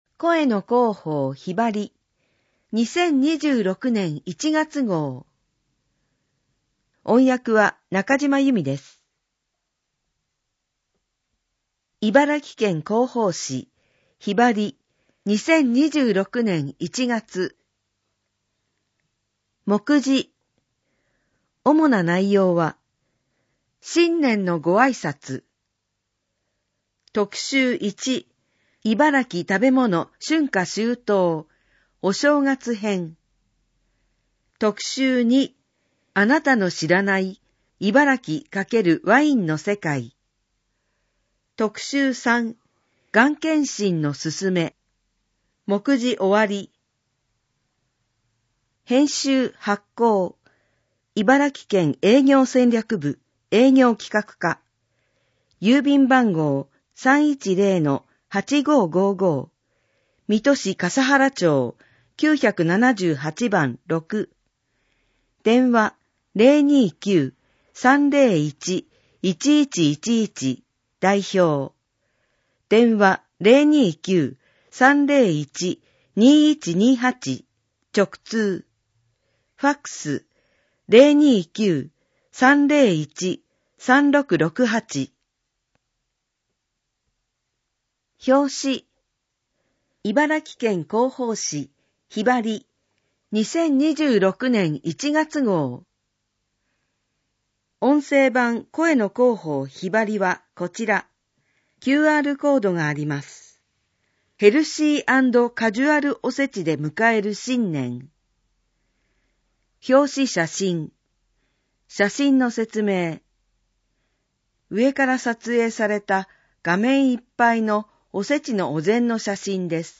声の広報「ひばり」 音声を再生するためには、 「QuicktimePlayer」（外部サイトへリンク） 、 「WindowsMediaPlayer」（外部サイトへリンク） 、 「RealPlayer」（外部サイトへリンク） （いずれも無料）などが必要です。